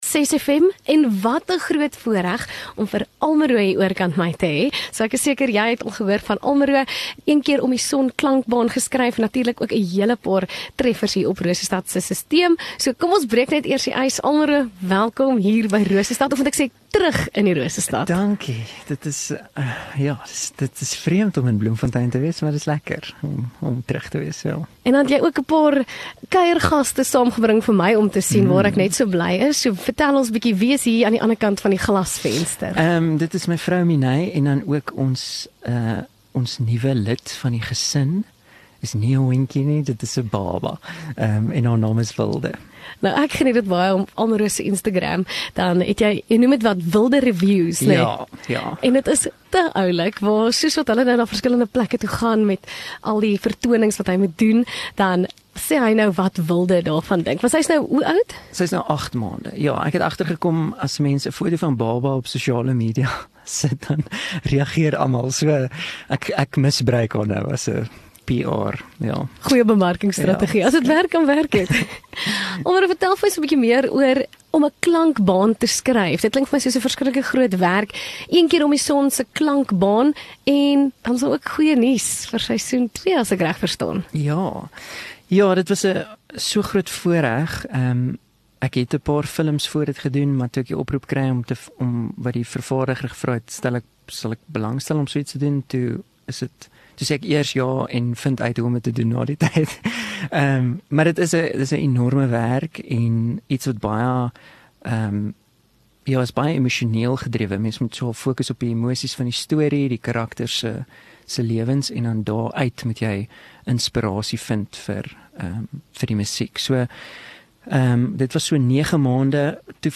Kunstenaar Onderhoude